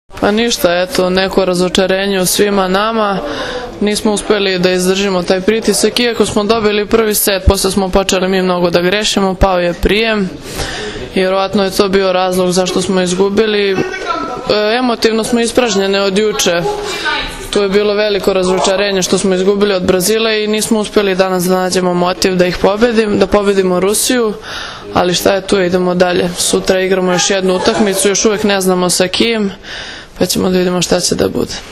IZJAVA BJANKE BUŠE